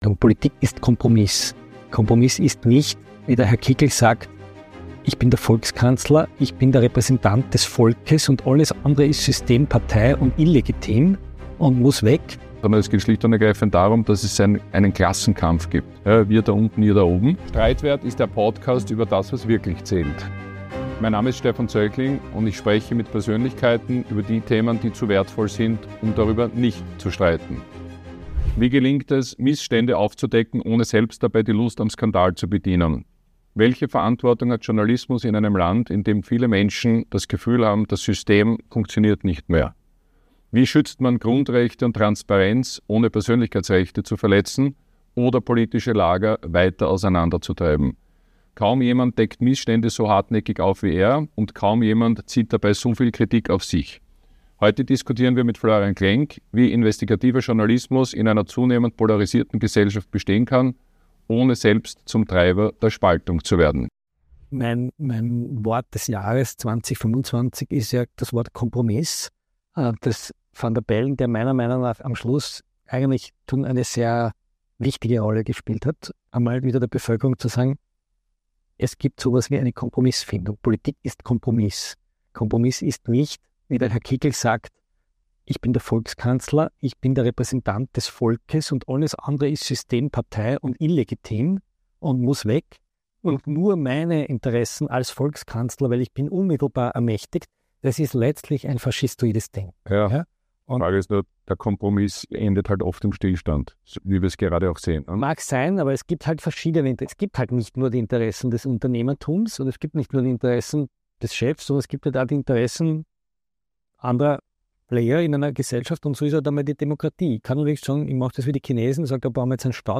In diesem Gespräch geht es nicht um Schlagzeilen, sondern um Grundsätzliches.